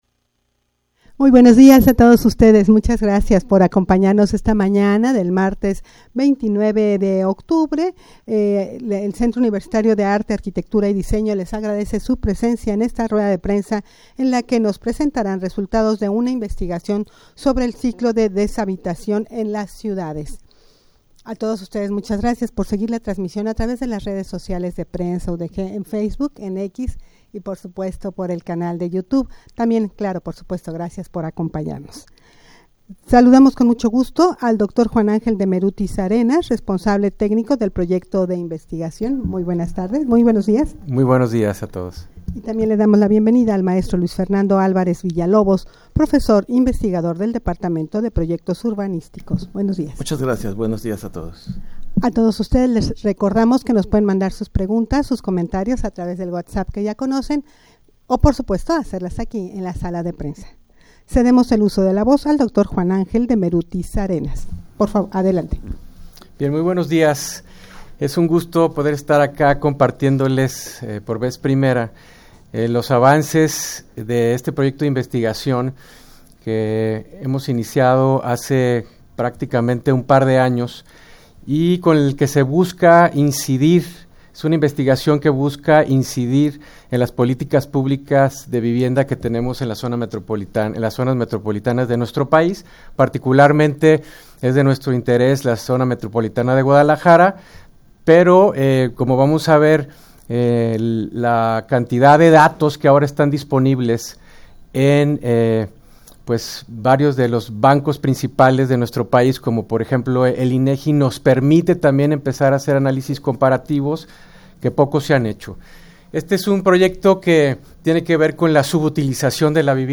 Audio de la Rueda de Prensa
rueda-de-prensa-para-presentar-resultados-de-una-investigacion-sobre-el-ciclo-de-deshabitacion-en-las-ciudades.mp3